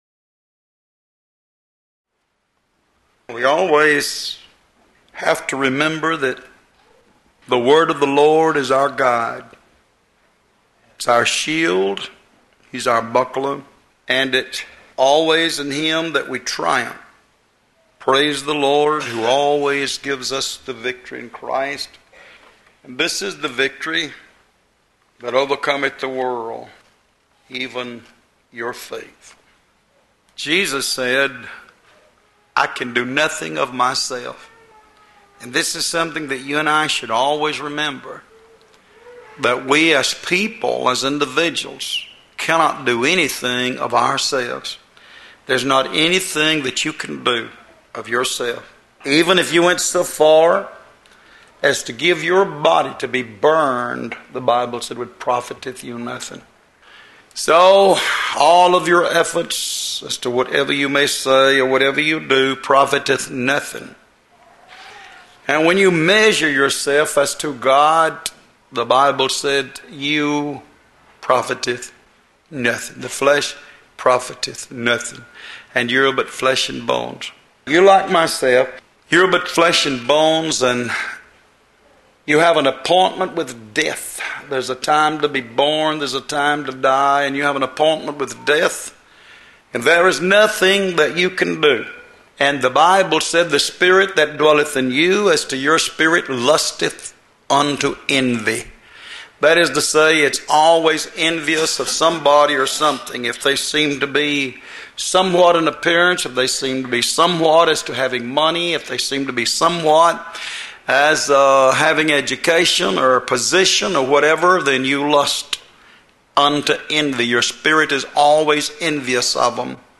Sermons Starting With ‘W’